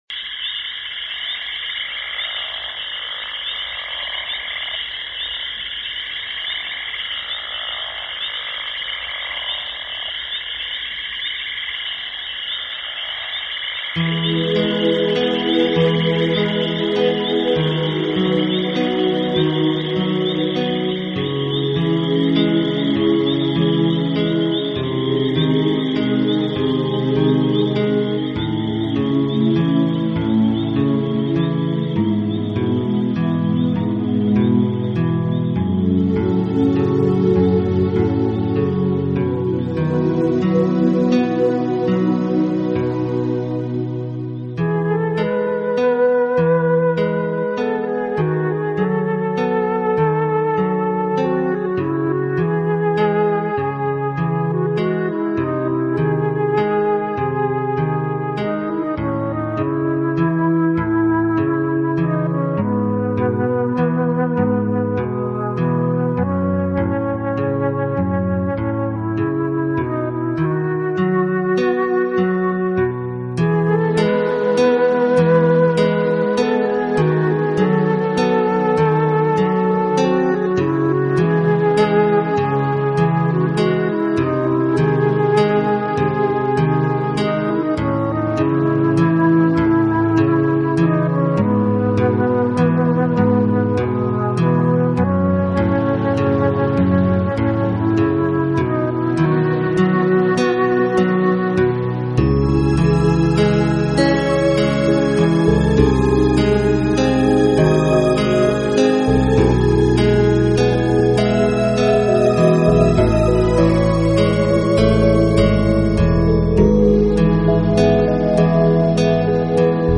新世纪纯音乐